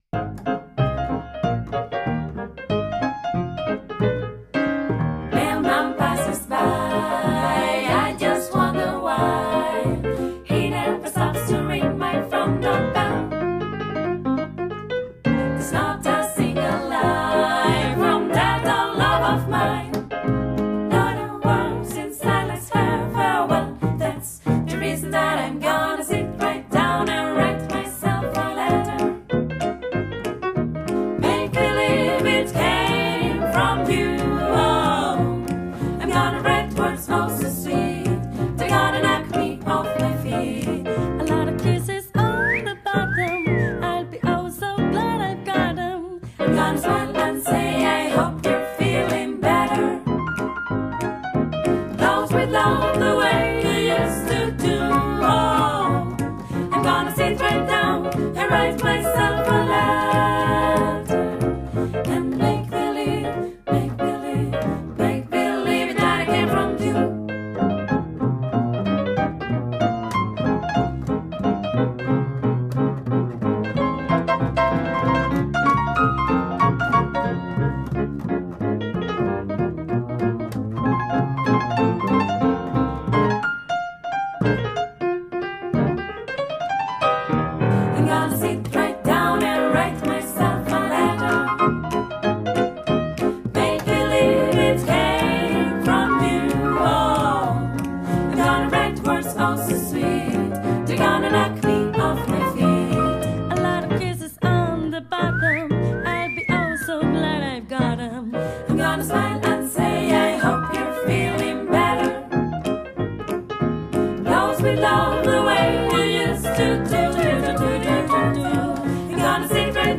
Y es que, durante la siguiente hora bailaremos con el mejor Blues del momento de la mano de la Blues Blast Magazine en bittorrent, UPVRadio y esta misma web.